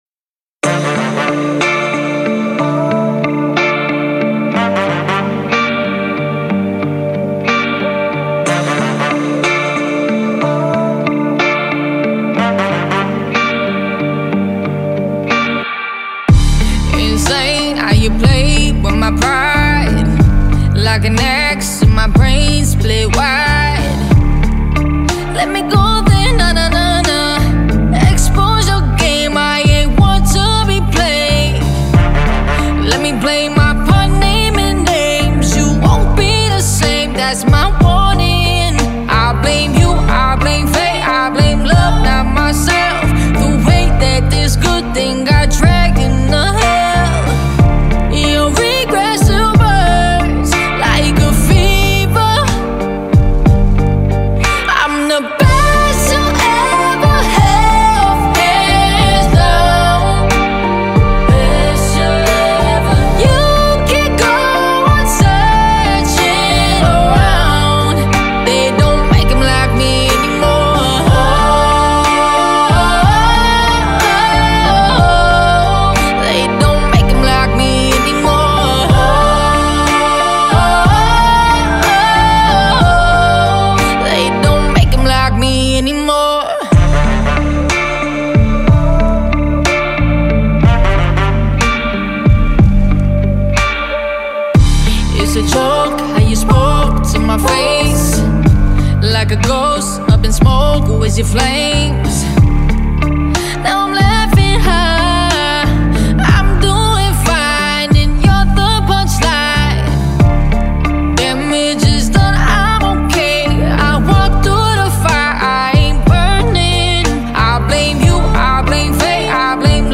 In-Studio Performance